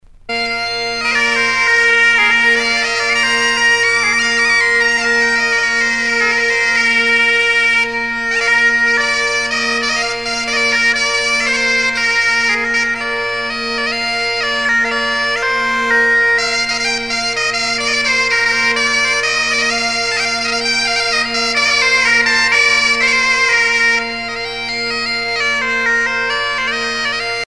danse : jabadao
Pièce musicale éditée